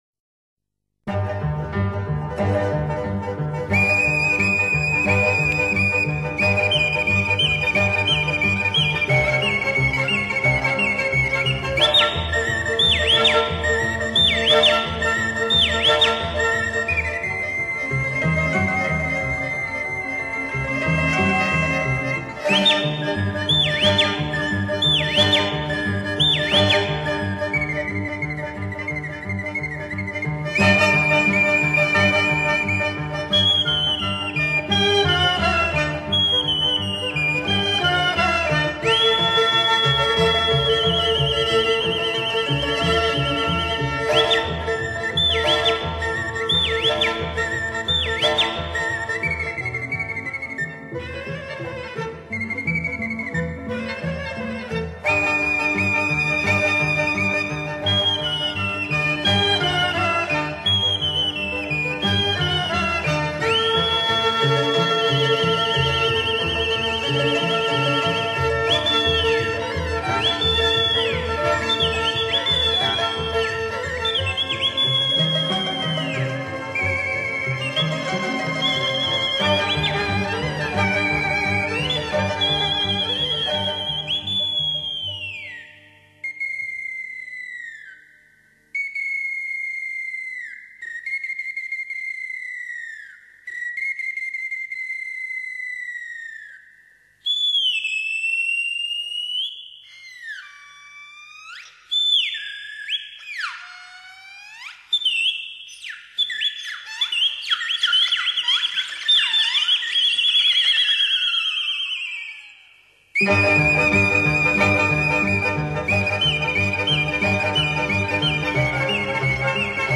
口笛